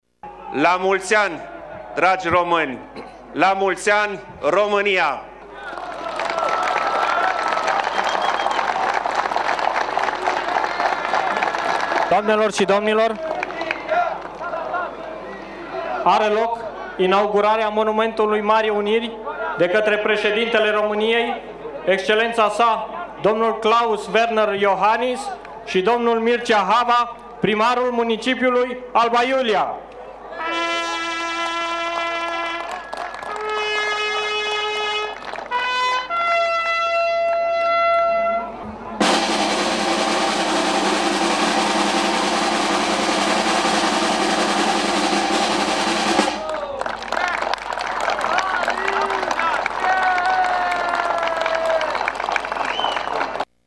Șeful statului a susținut o alocuțiune, iar acum asistă  la parada militară: